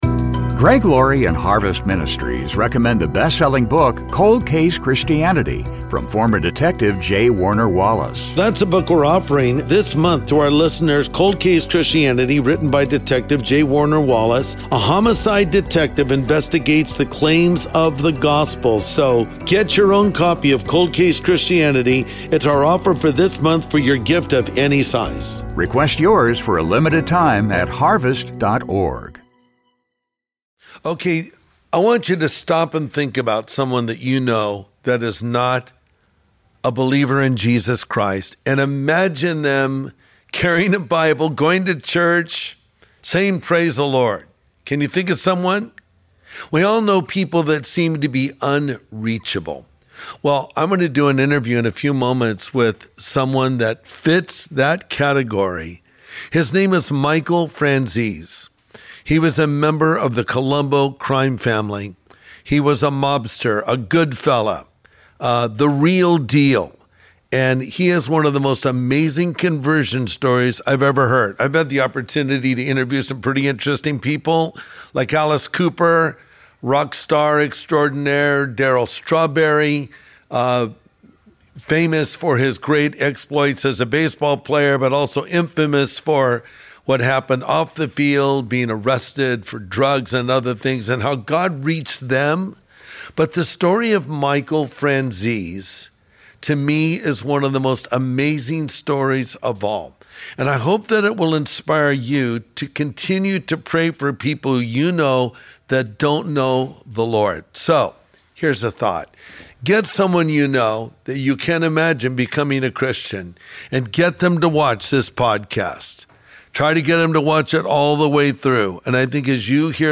An Ex-Mobster's Testimony: Mafia Life, Finding God in Prison, and Emulating Jesus' Manhood (Michael Franzese Interview)
Former top Mafia member, Michael Franzese, joins Pastor Greg Laurie to share one of the most captivating stories ever discussed on this podcast.